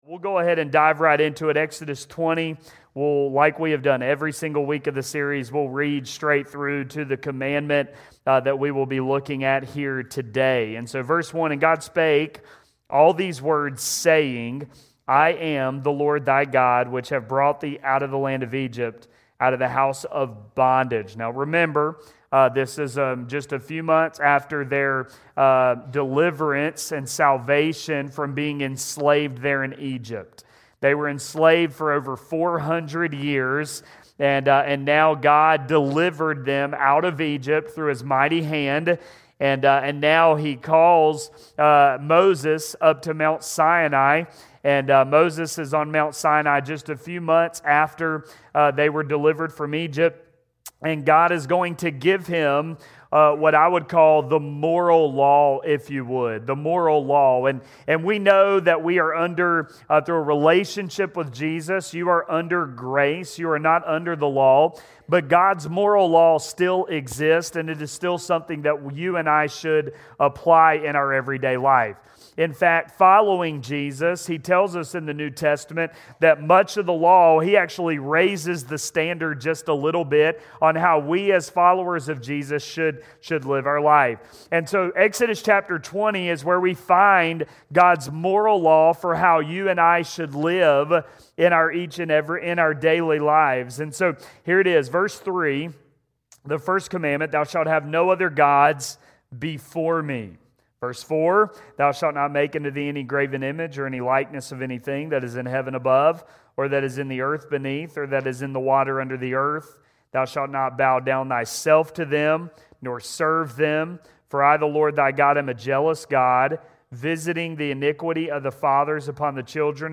From everyday dishonesty to the deeper heart issues of gossip, slander, half-truths, and character-damaging words, this sermon shines a light on the subtle ways we can misuse our words and misrepresent the truth. More than a rule to follow, the Ninth Commandment invites us to reflect the character of a God who is truth.